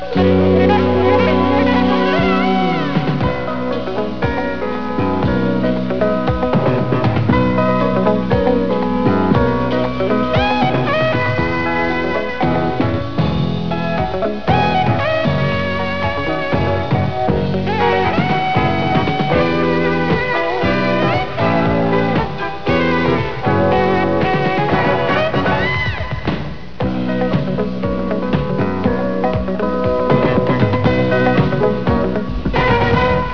TV Themes